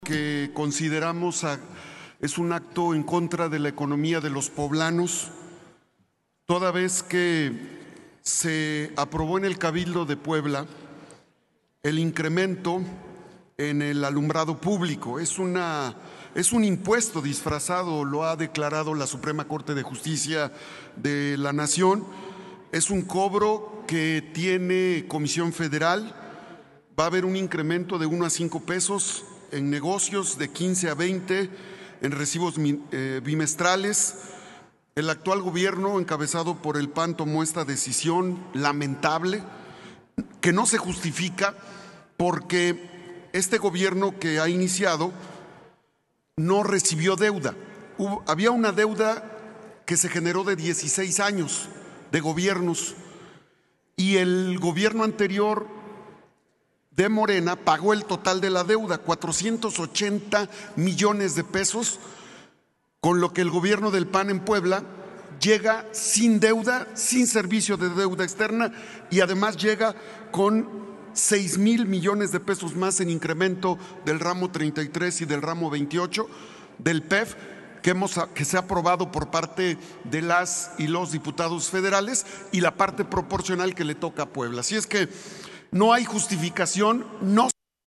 El senador de Morena Alejandro Armenta Mier